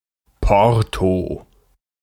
Ääntäminen
Synonyymit dégaine cluse Ääntäminen France: IPA: [pɔʁ] Tuntematon aksentti: IPA: /pɔʁt/ Haettu sana löytyi näillä lähdekielillä: ranska Käännös Ääninäyte Substantiivit 1.